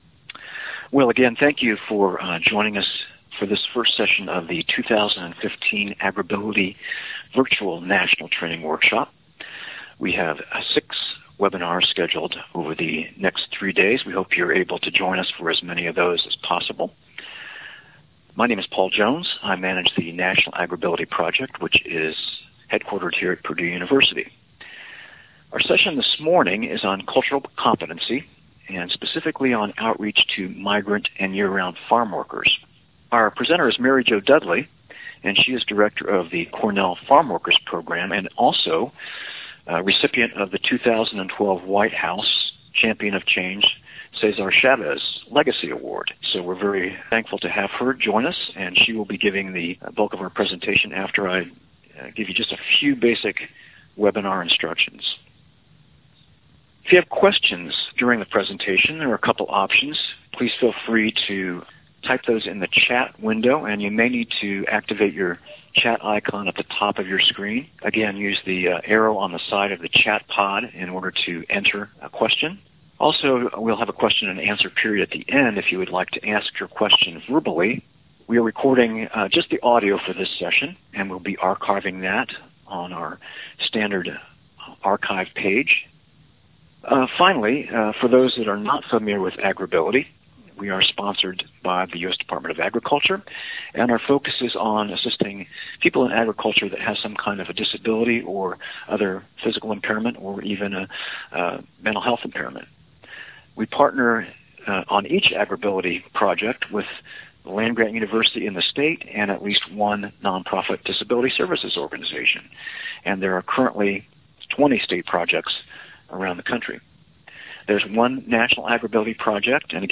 Cultural Competency: Outreach to Migrant and Year-round Farmworkers (audio only)